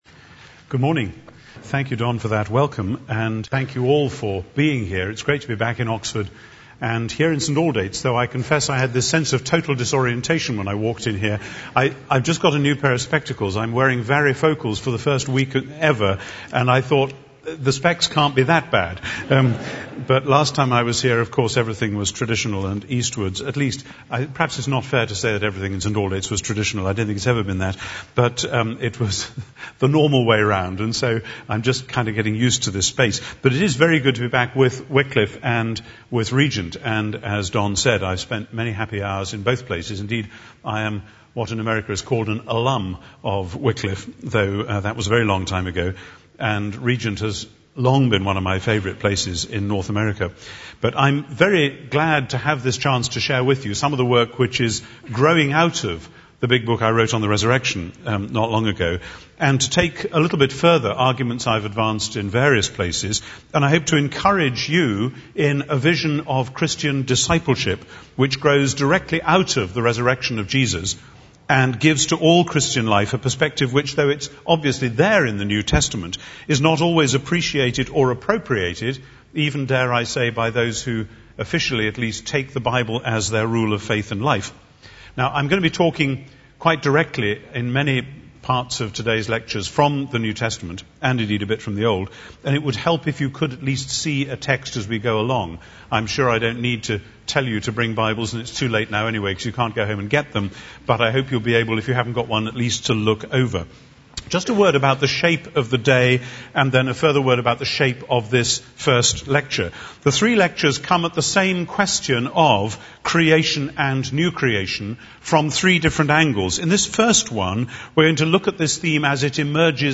Creation and New Creation in the New Testament Audiobook
Narrator
3.3 Hrs. – Unabridged